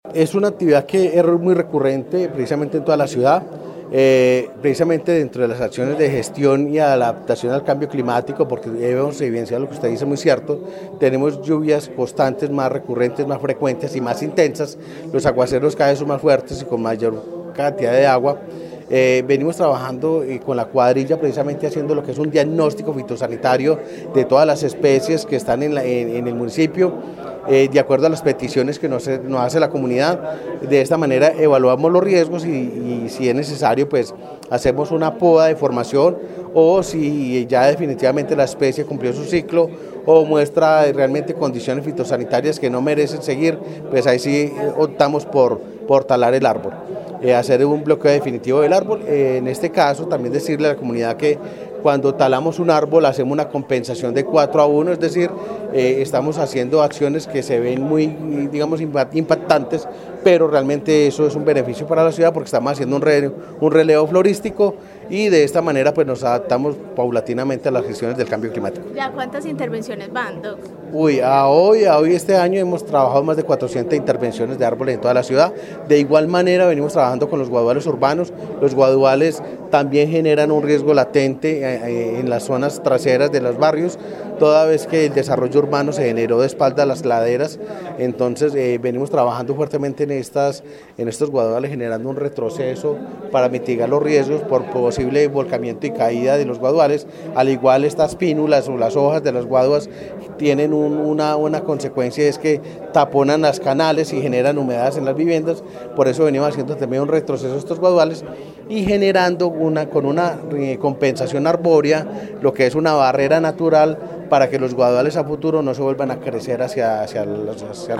Director de planeación de Armenia